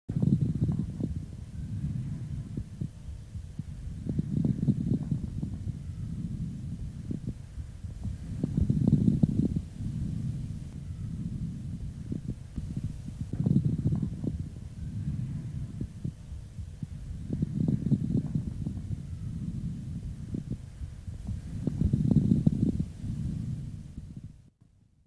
Звуки отдышки
Хрипы влажные средне и крупнопузырчатые